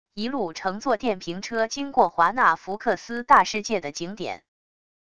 一路乘坐电瓶车经过华纳福克斯大世界的景点wav音频